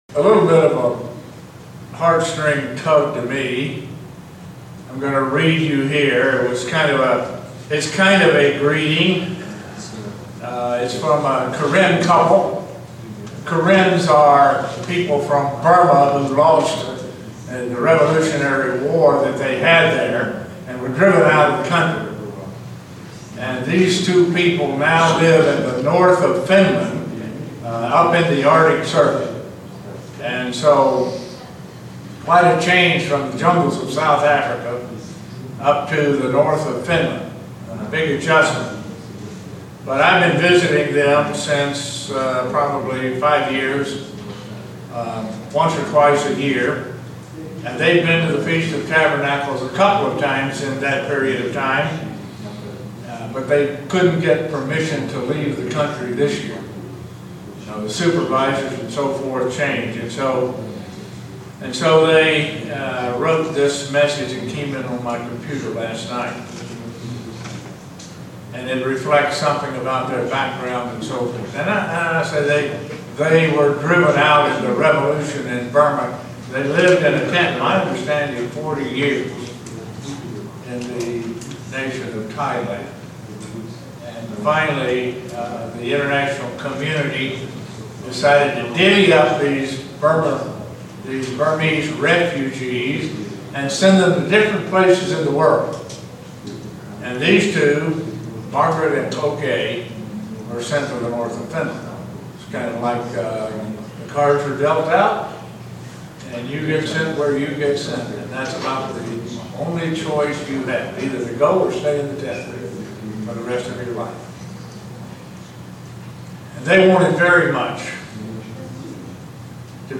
Print The promise of God that the glory of the later house will be greater than the glory of the former house. sermon Studying the bible?